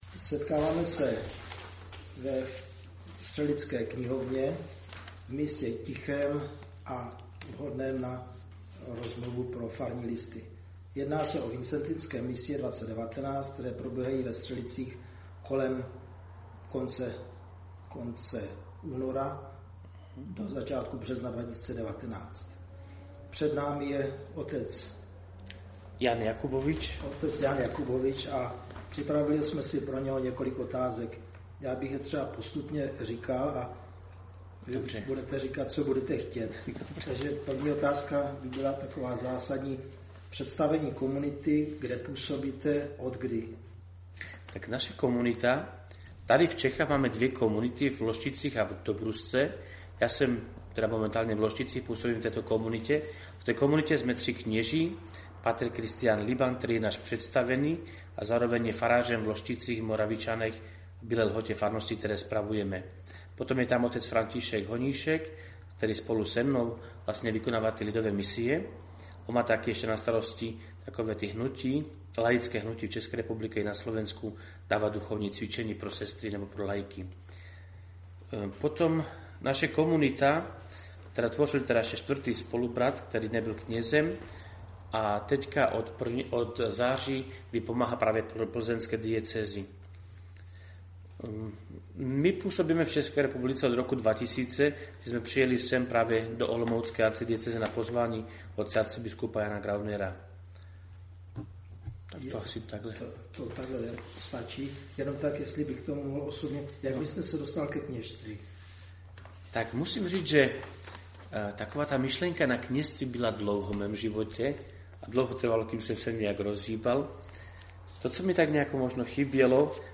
Misie STŘELICE 2019 přednášky (1 část).
Přehrávání + ukládání: Píseň o svatém 0.9 MB 64 kb/s mp3 Píseň o svatém 2.7 MB 192 kb/s mp3 Přehrávání (streaming): není k dispozici Ukládání: není k dispozici Otázky v místní knihovně (24 min.)
otazky-v-knihovne-64kb.mp3